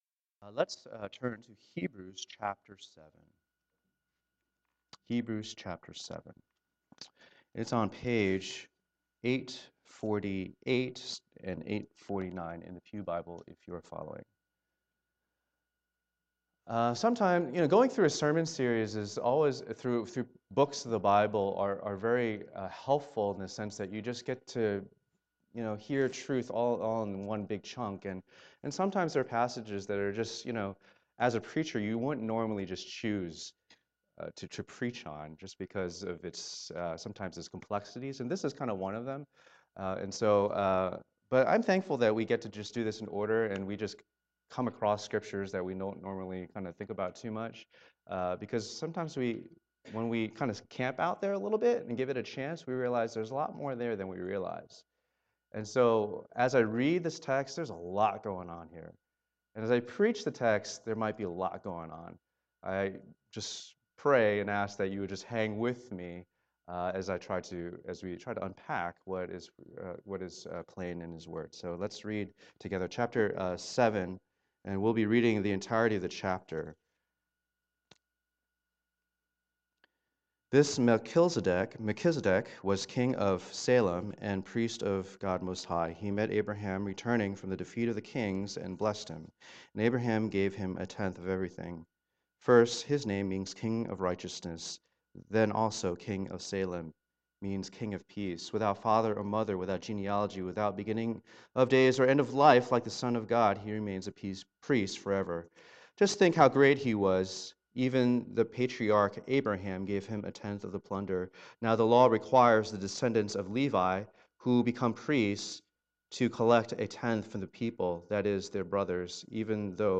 Supremacy of Jesus in all things Passage: Hebrews 7:1-28 Service Type: Lord's Day %todo_render% « Jesus